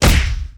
PunchHit5.wav